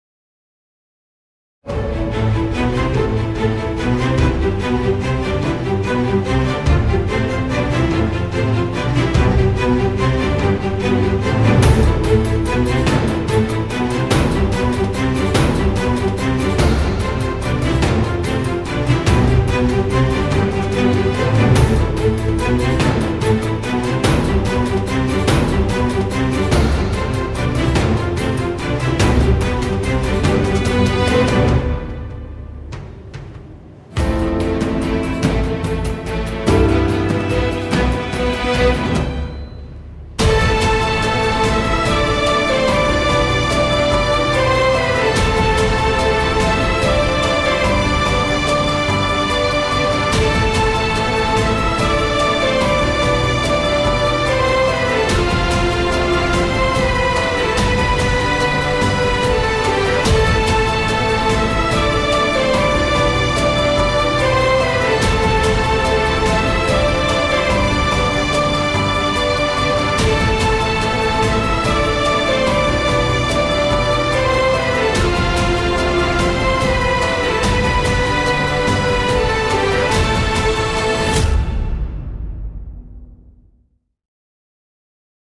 tema dizi müziği, duygusal mutlu heyecan fon müziği.